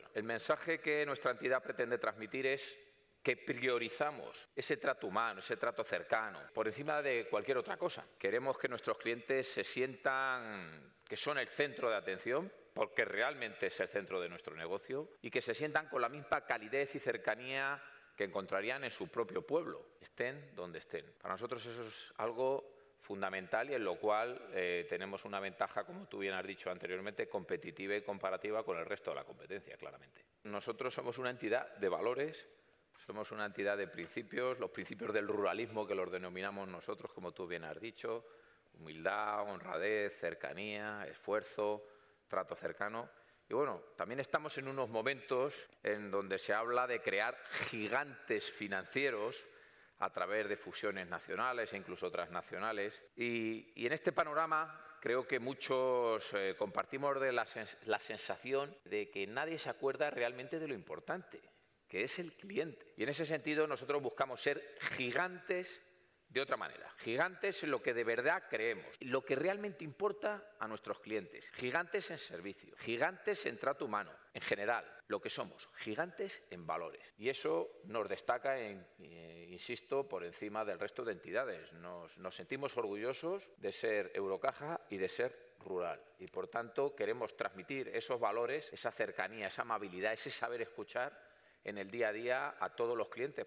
Los distintos spots han sido rodados en una oficina real de Eurocaja Rural.